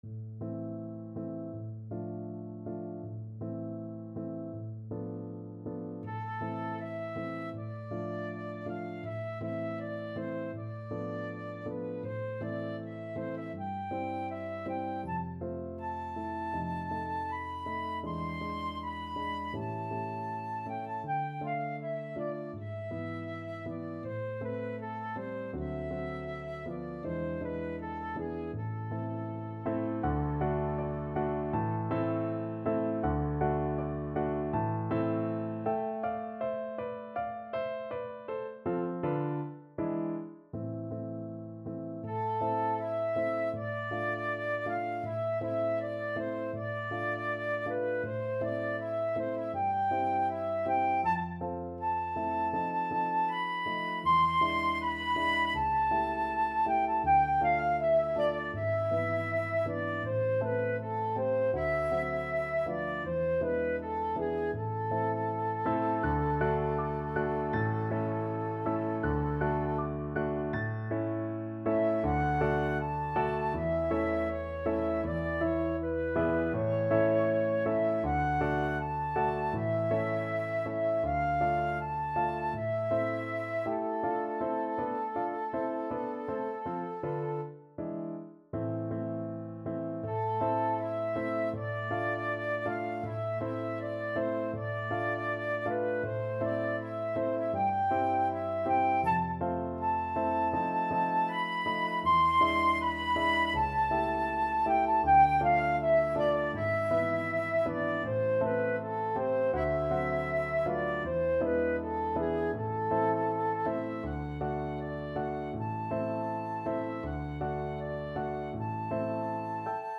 Free Sheet music for Flute
~ = 100 Andante
A minor (Sounding Pitch) (View more A minor Music for Flute )
2/4 (View more 2/4 Music)
Classical (View more Classical Flute Music)
jarnefelt_berceuse_FL.mp3